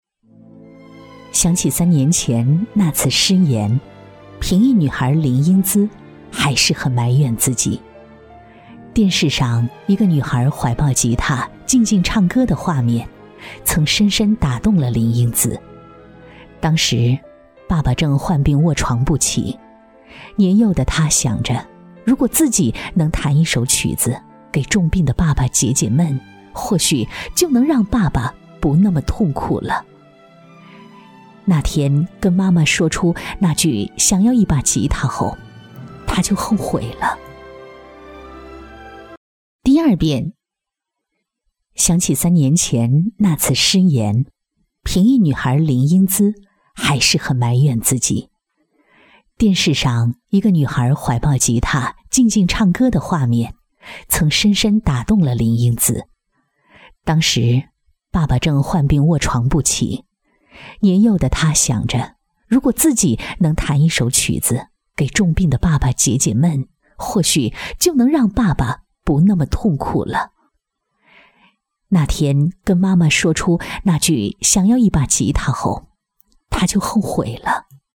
• 女S114 国语 女声 宣传片 鲁南商报 专题片 沉稳 亲切甜美